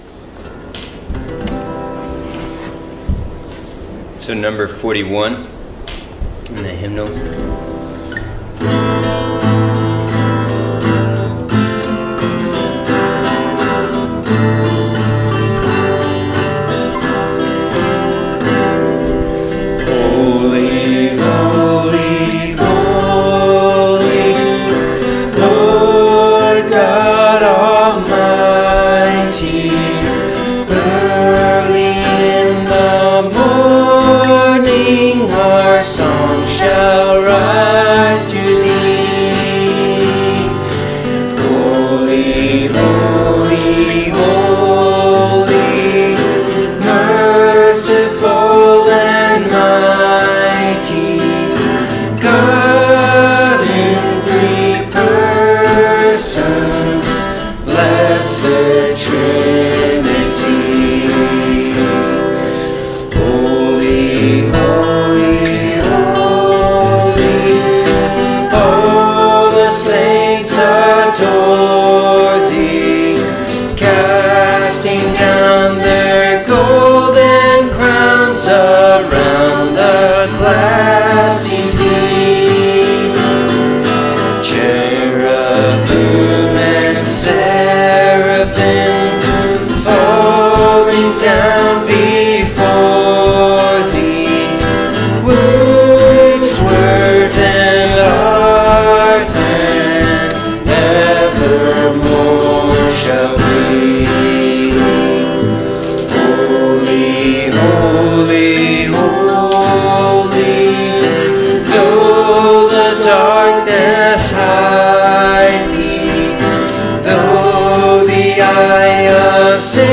3/15 – Sunday Worship Hymns
March-15-Hymns.mp3